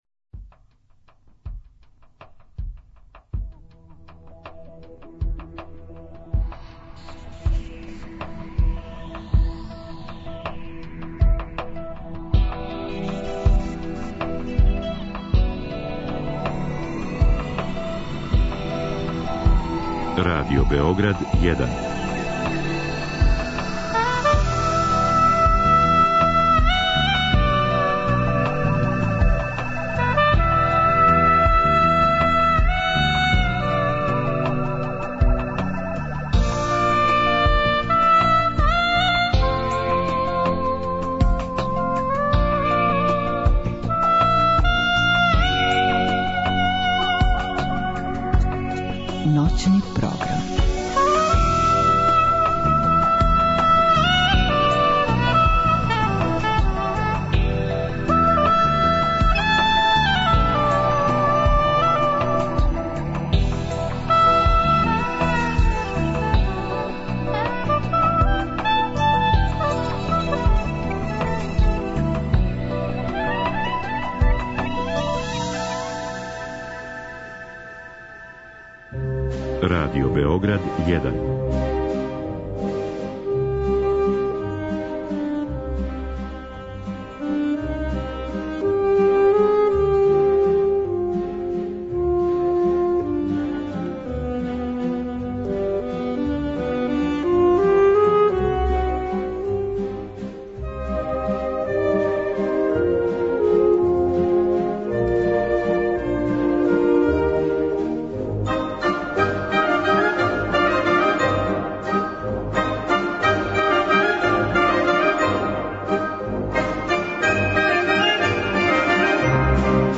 Термин од 2 до 4 ујутру резервисан је за камерну и симфонијску музику, те ћете слушати композиције Александра Глазунова, Леоша Јаначека, Петра Иљича Чајковског и Густава Малера.